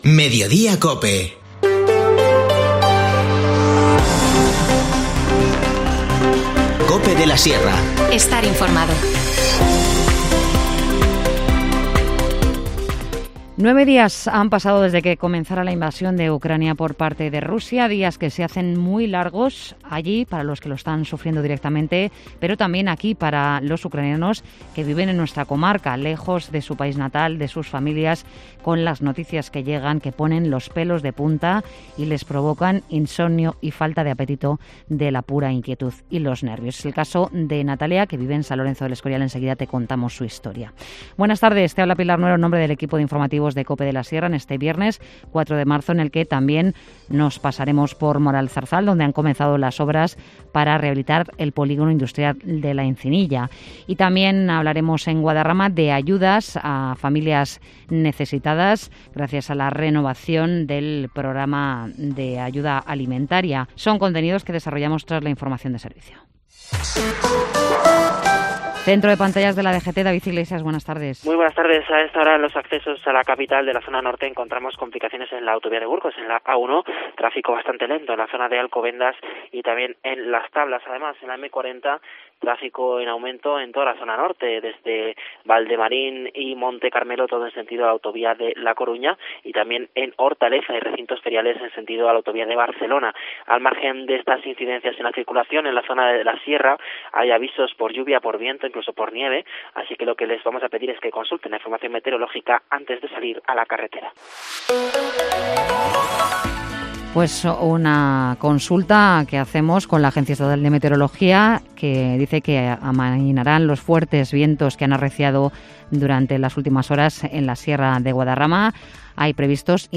Informativo Mediodía 4 marzo